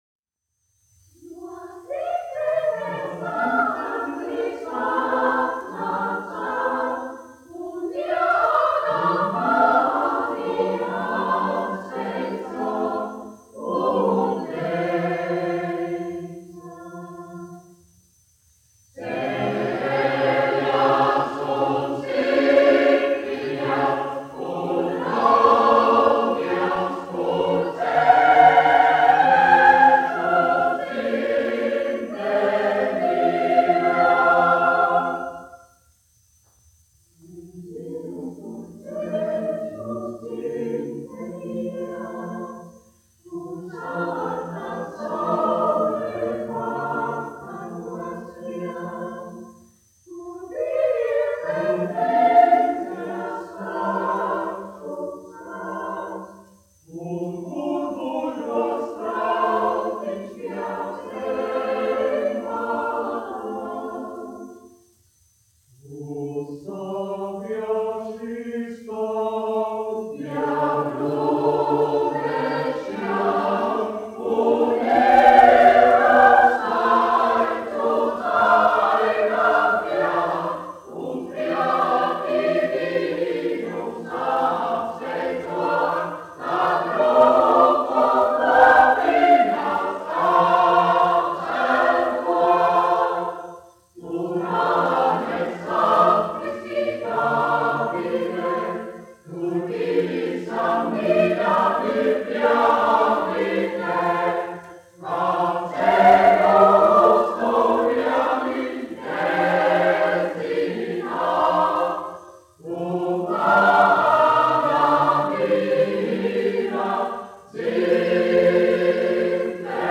1 skpl. : analogs, 78 apgr/min, mono ; 25 cm
Kori (jauktie)
Latvijas vēsturiskie šellaka skaņuplašu ieraksti (Kolekcija)